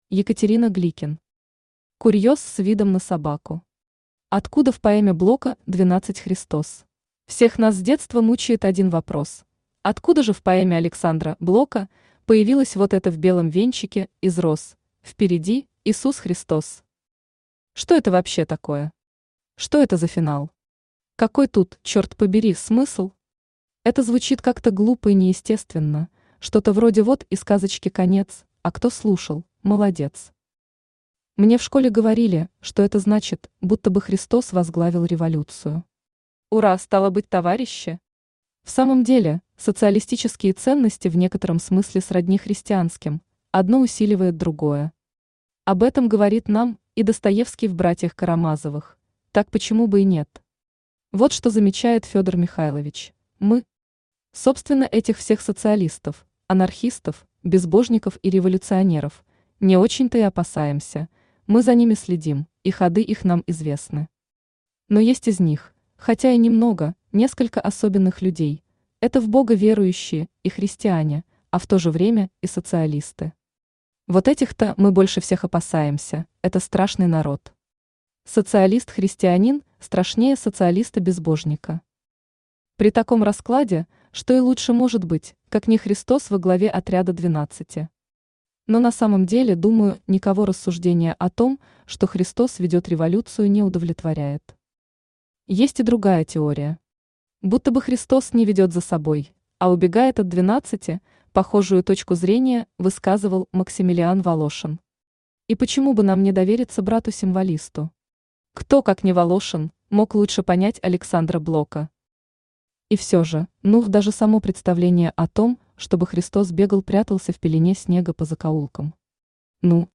Откуда в поэме Блока «12» Христос Автор Екатерина Константиновна Гликен Читает аудиокнигу Авточтец ЛитРес.